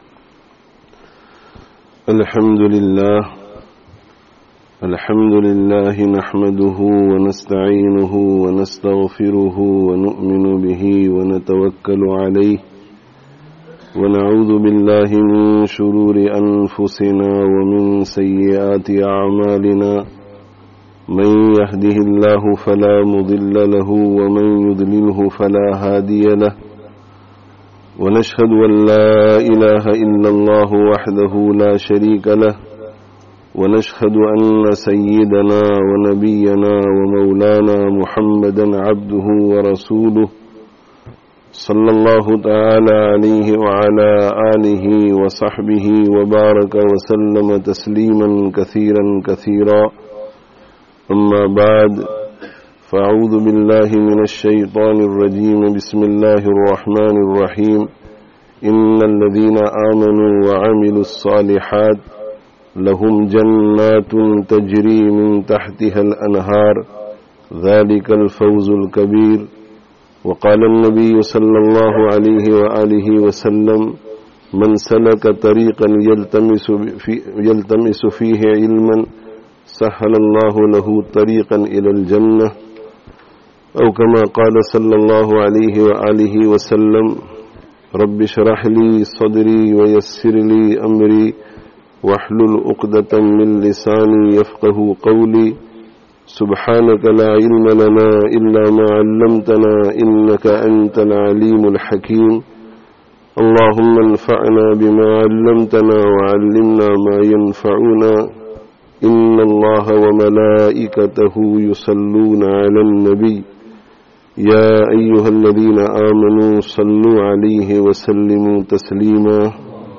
'Ilm: The Key to Success [Opening of Zakaria Masjid and Education Centre] (Dewsbury 22/09/19)